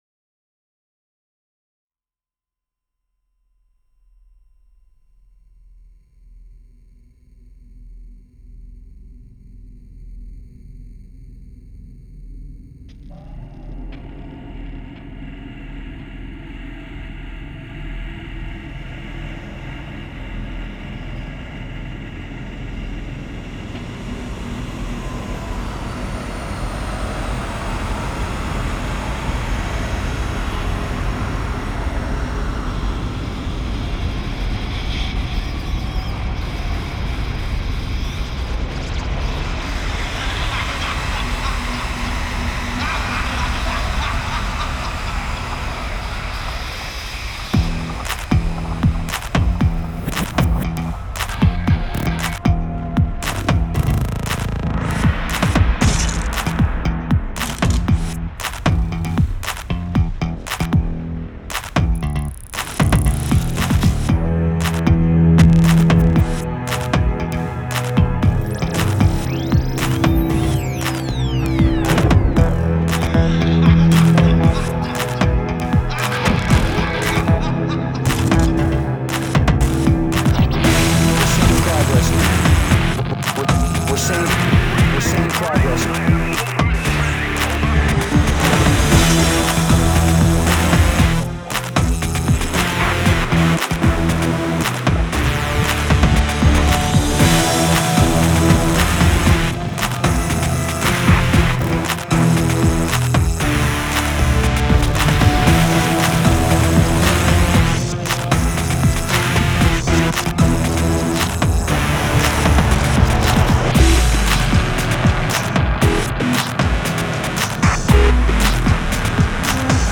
pure sensitive electronic music
Grinding sounds straight out from the boring hell of europe.